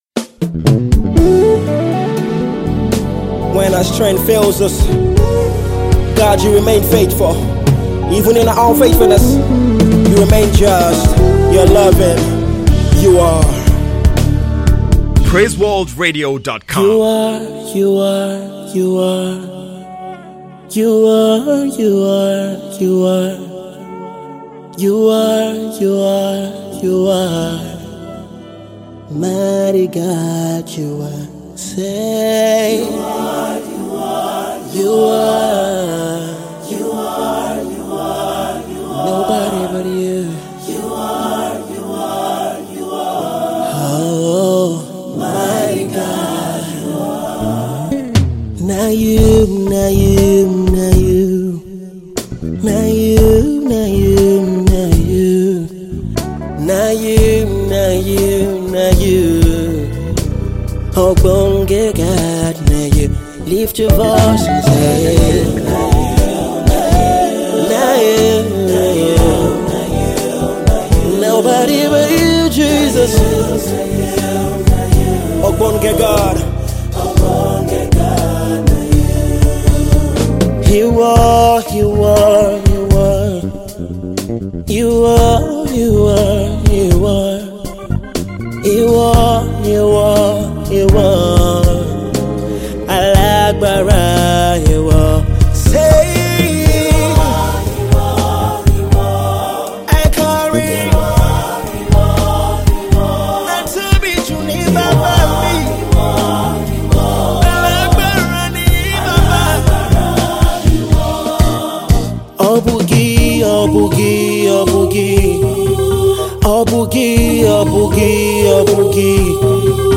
worship song